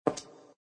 tap.ogg